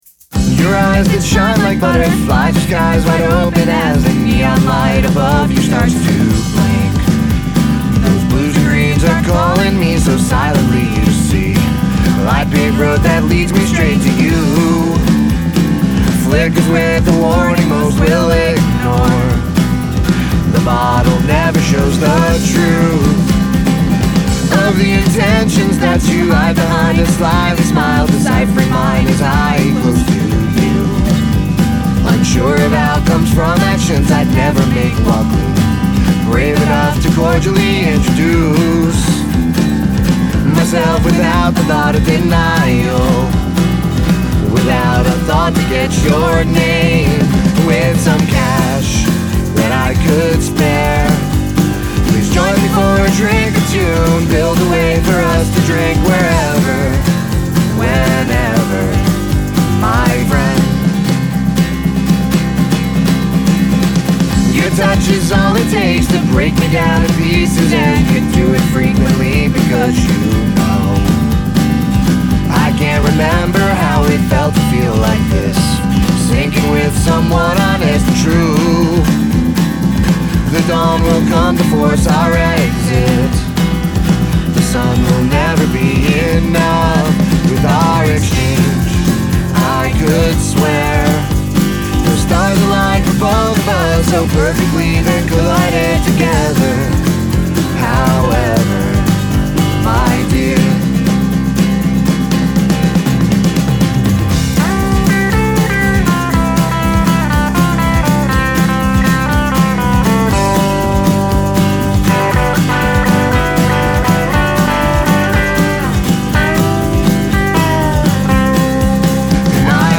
Vocals, Guitar, Drums